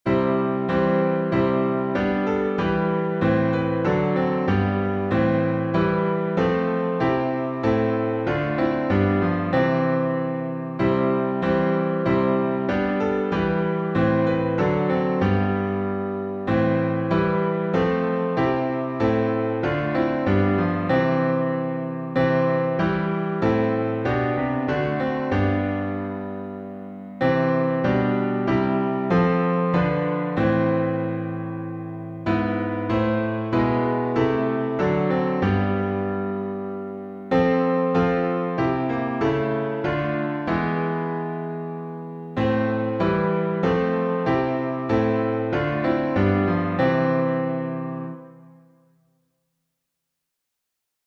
A Mighty Fortress Is Our God — C Major, alternate two.